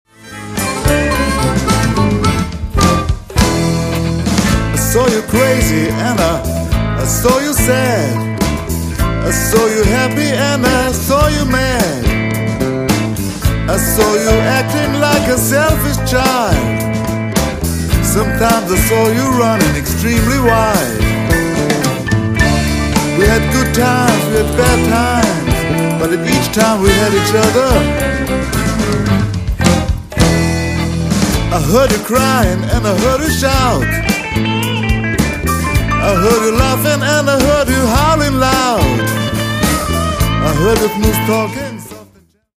guitars, lead vocals
bass, backing vocals, hammond organ
drums & percussion